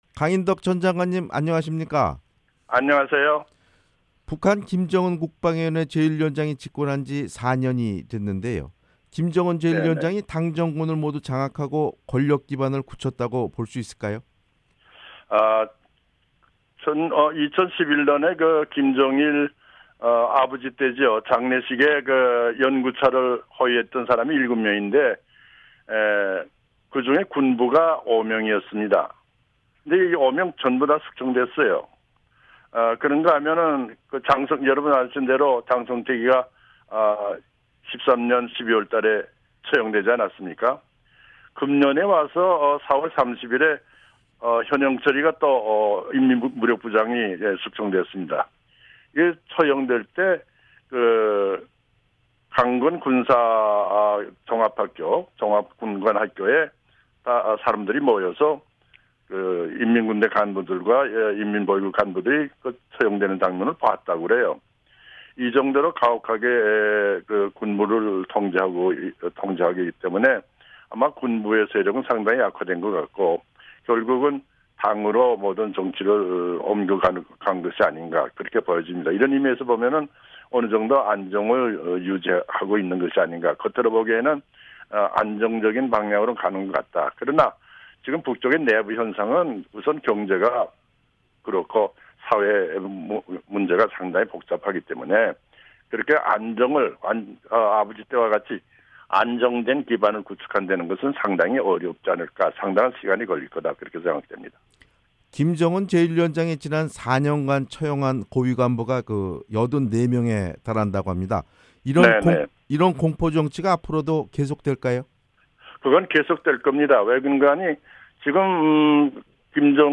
[인터뷰] 강인덕 전 한국 통일 장관